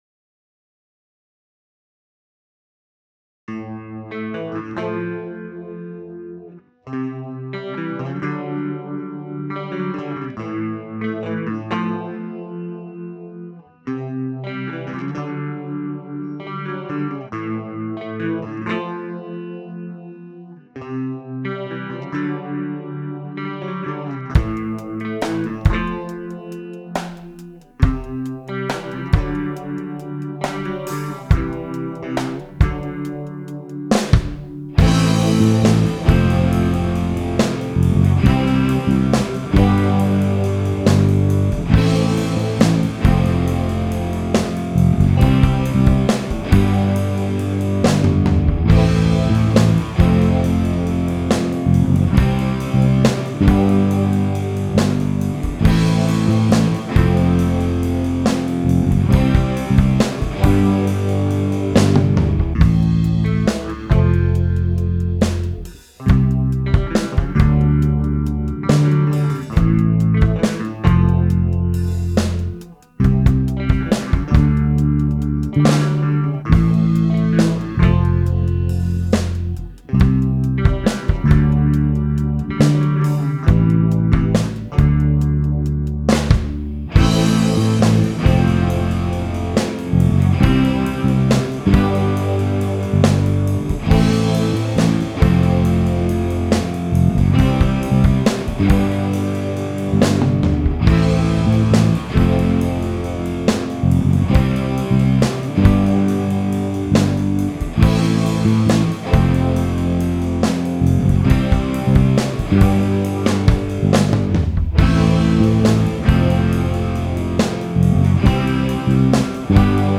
Country DEMO https